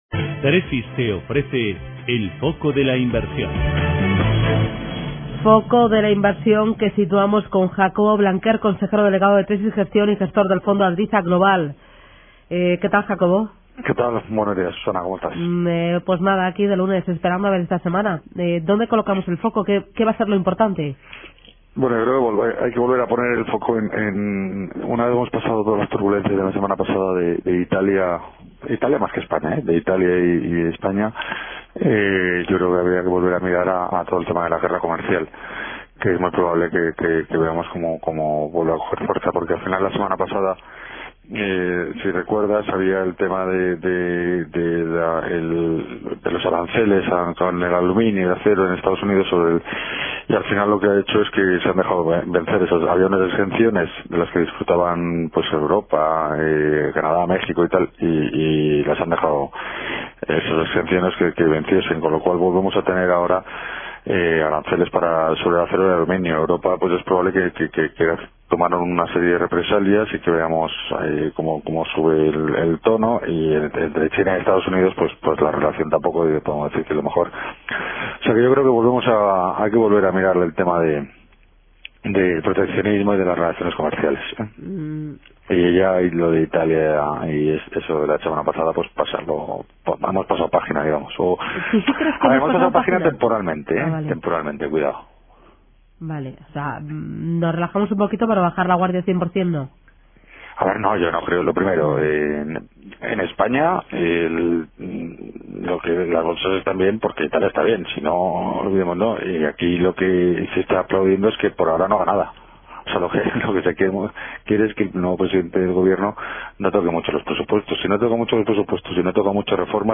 En la radio
En Radio Intereconomía todas las mañanas nuestros expertos analizan la actualidad de los mercados.